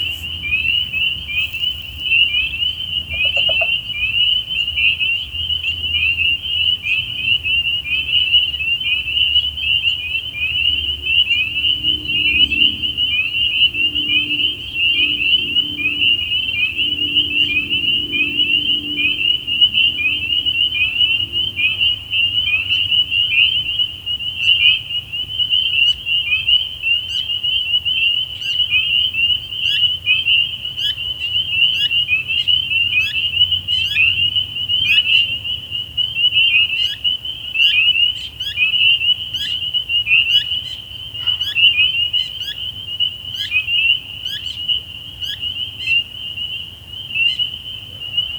Spring Peepers
This was recorded about a week and a half ago (late April), just down the street at a cattail-lined drainage pond in the neighborhood. Hundreds of little frogs about the size of your thumbnail, calling to each other from dusk until about 2 hours after sunset.
I think I can hear a couple other species mixed in as well. A chorus of frogs!